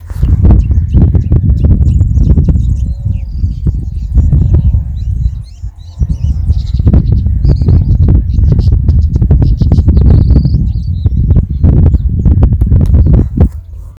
White-banded Mockingbird (Mimus triurus)
Location or protected area: San Salvador
Condition: Wild
Certainty: Observed, Recorded vocal